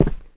step1.wav